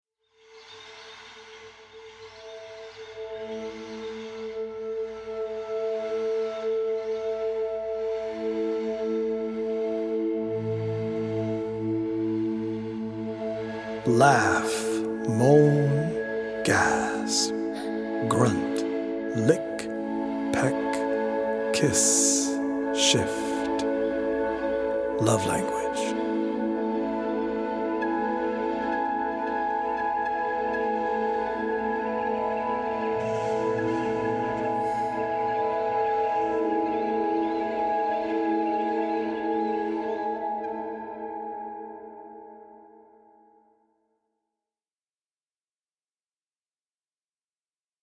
audio-visual poetic journey
healing Solfeggio frequency music